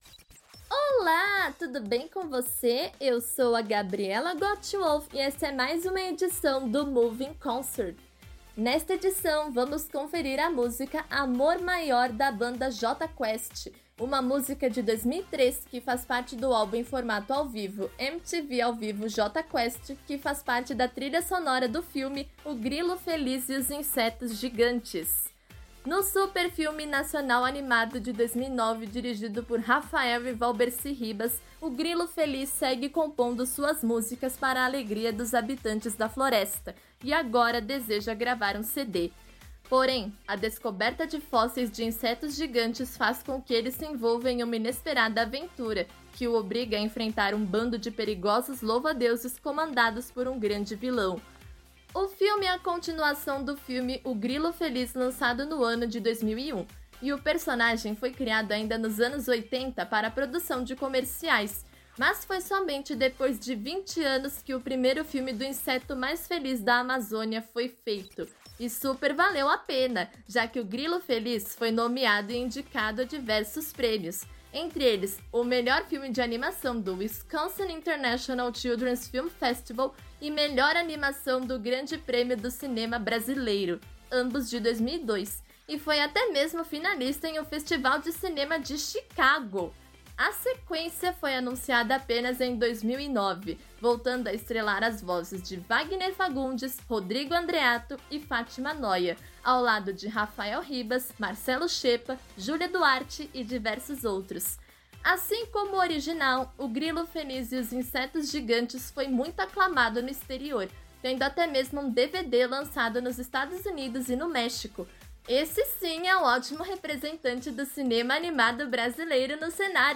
ao vivo
com sua mensagem otimista e melodia envolvente